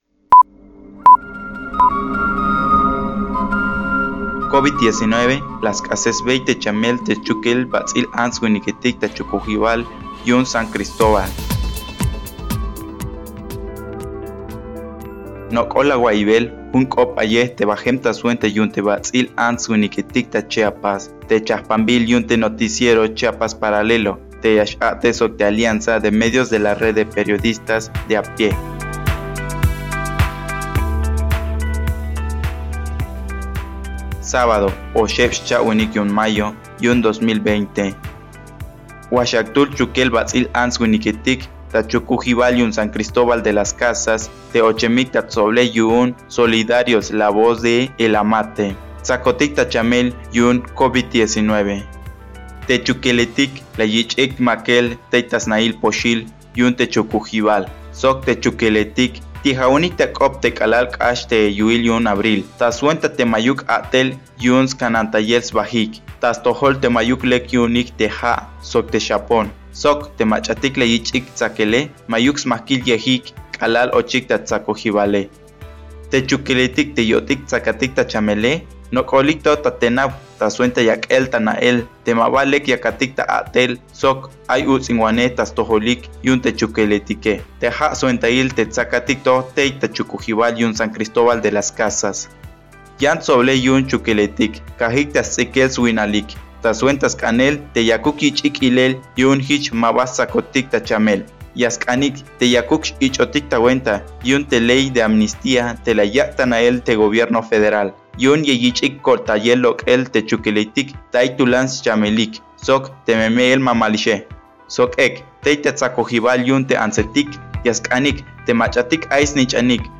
Traducción y locución